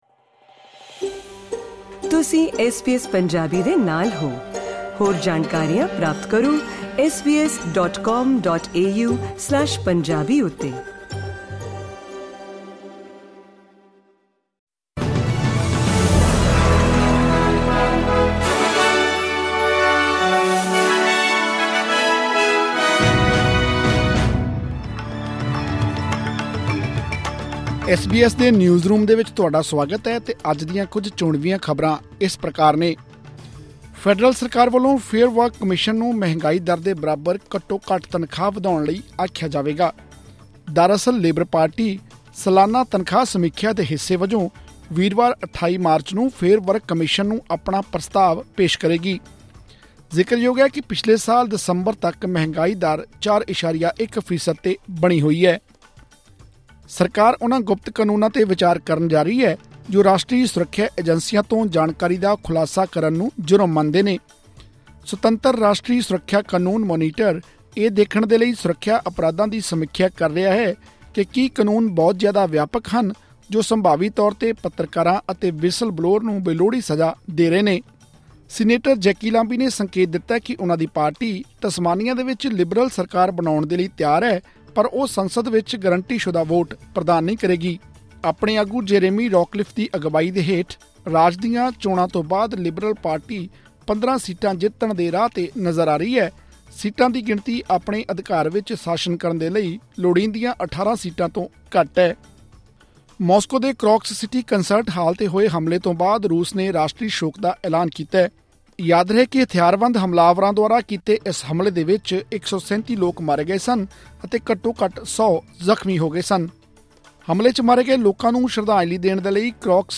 ਐਸ ਬੀ ਐਸ ਪੰਜਾਬੀ ਤੋਂ ਆਸਟ੍ਰੇਲੀਆ ਦੀਆਂ ਮੁੱਖ ਖ਼ਬਰਾਂ: 25 ਮਾਰਚ, 2024